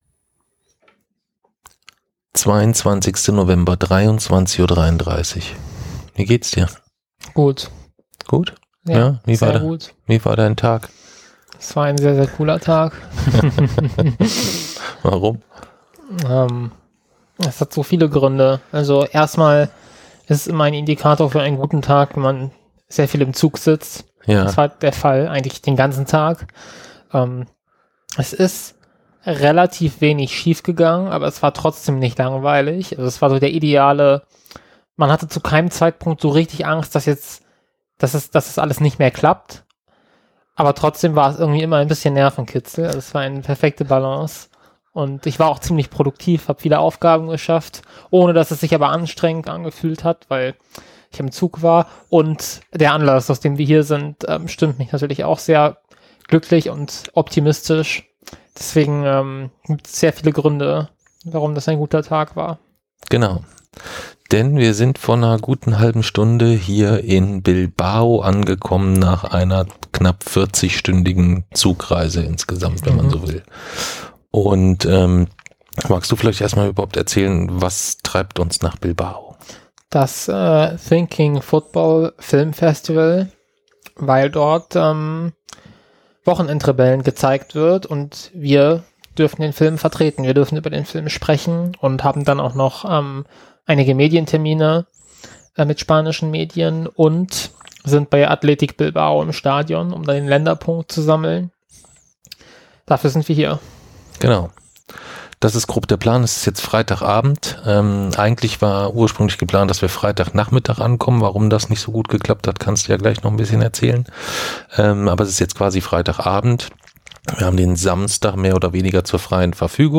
Mit Blick auf Bilbao erzählen wir davon, wie wir beide unabhängig voneinander daran gescheitert sind, nach Spanien zu kommen, wie wir dann doch angekommen sind und was hier jetzt in den nächsten Tagen anliegt.